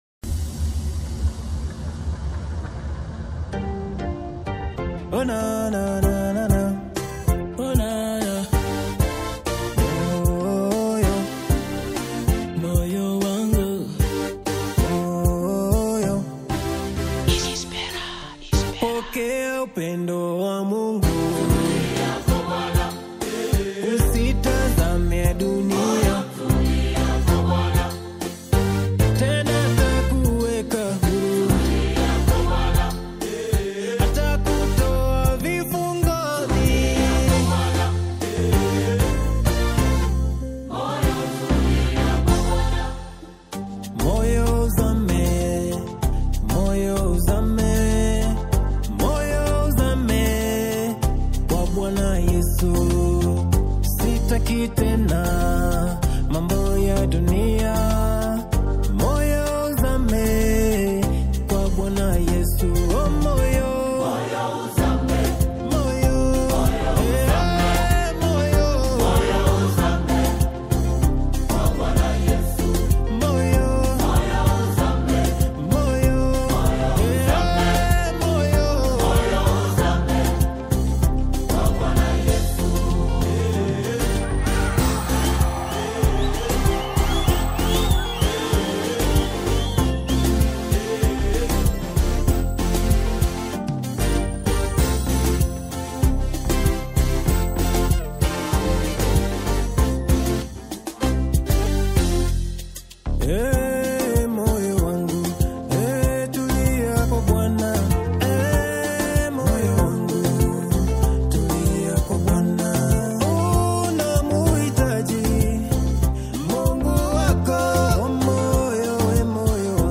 The explosive and undeniably infectious live single